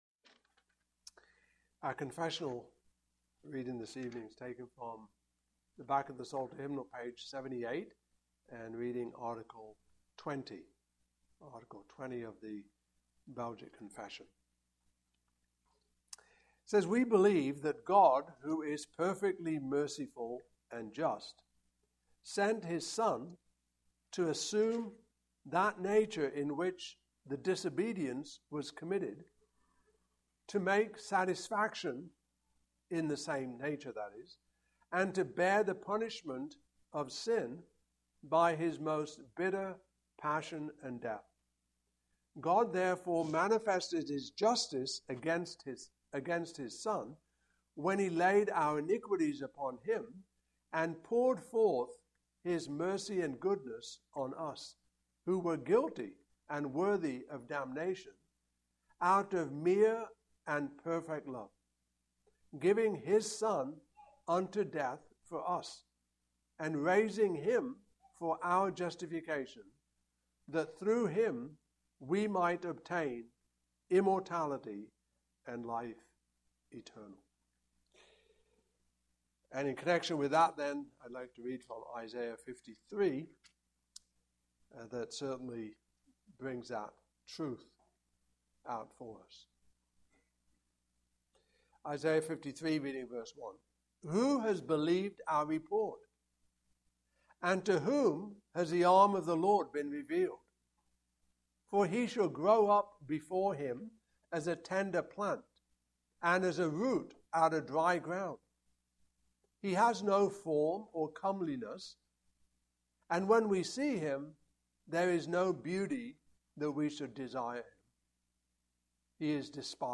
Belgic Confession 2025 Passage: Isaiah 53:1-12 Service Type: Evening Service Topics